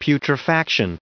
1985_putrefaction.ogg